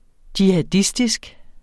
Udtale [ djihaˈdisdisg ]